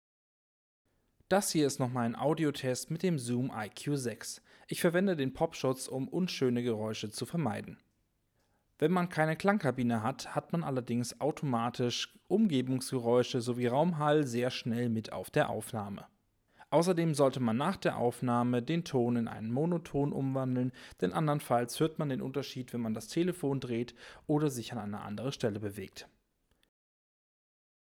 Sprachaufnahme mit 15 cm Abstand:
ZoomSpracheDEMO.mp3